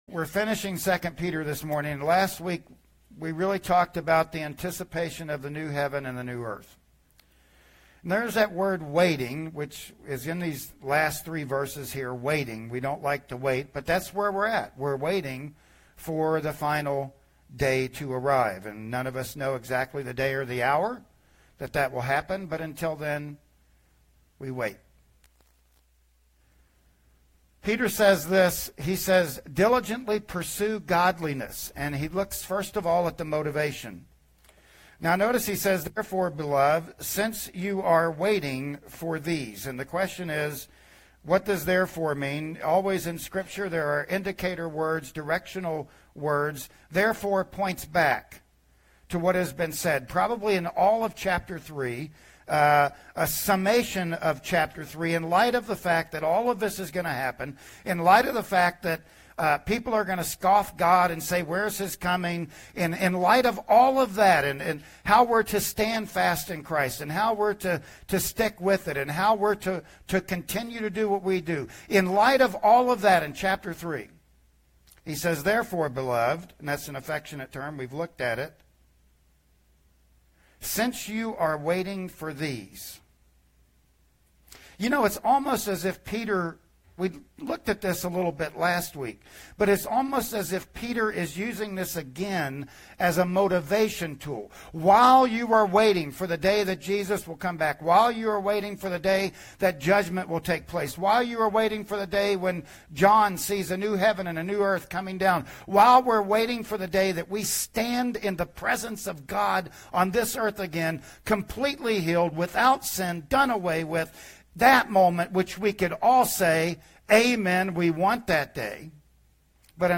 "2 Peter 3:14-18" Service Type: Sunday Morning Worship Service Bible Text